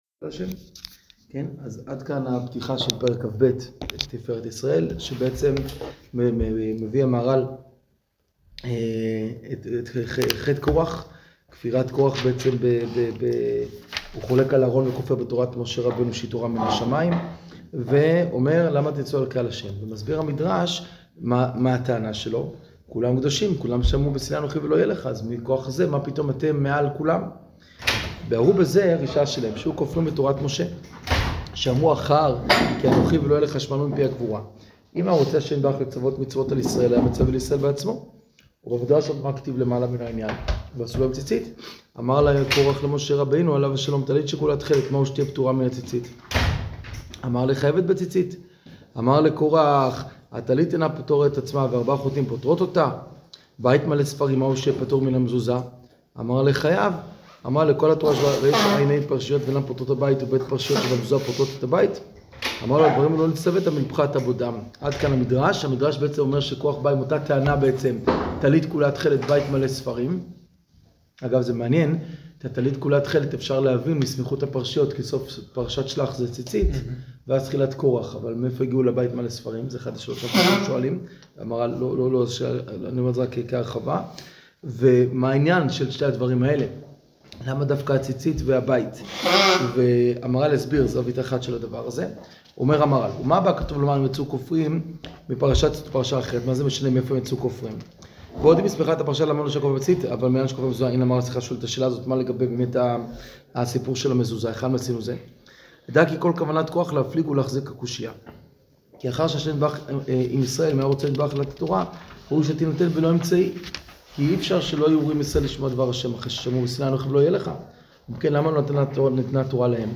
שיעור תפארת ישראל פרק כב